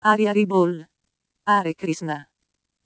Italian Chanting.mp3